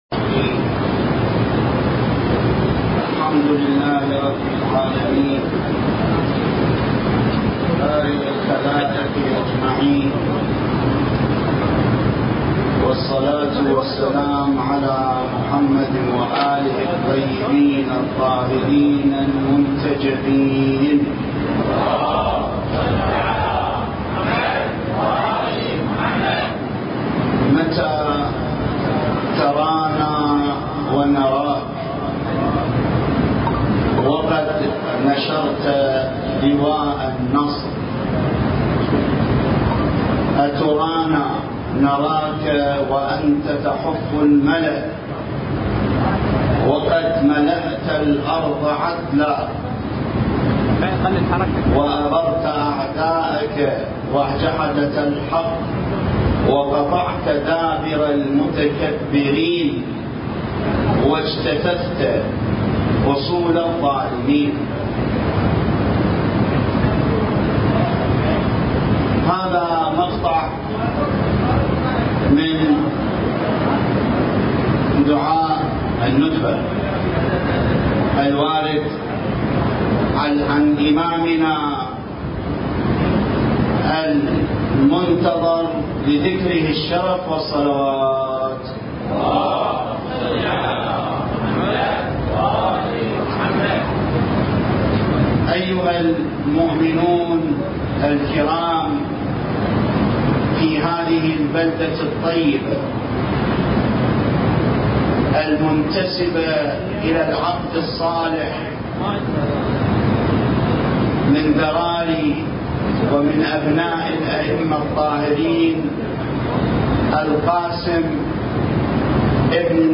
المكان: هيئة غريب سورى - بابل / مدينة القاسم المقدسة